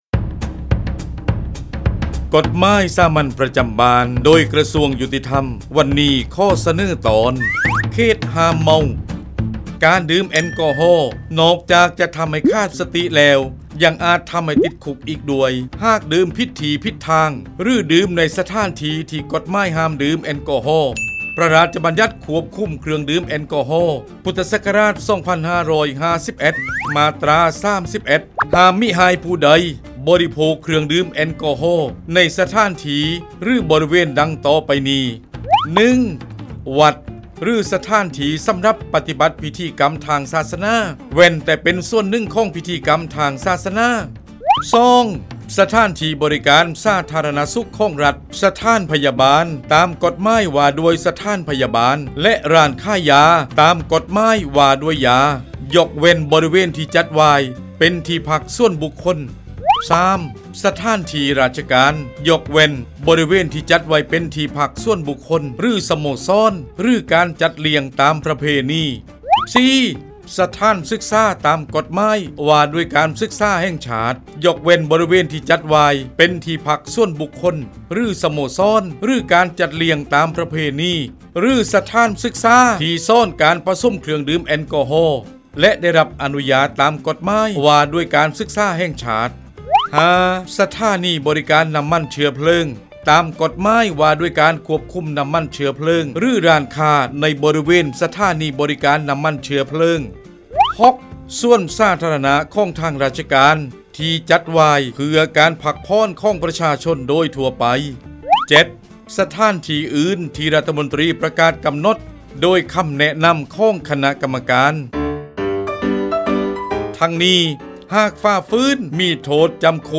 กฎหมายสามัญประจำบ้าน ฉบับภาษาท้องถิ่น ภาคใต้ ตอนเขตห้ามเมา
ลักษณะของสื่อ :   บรรยาย, คลิปเสียง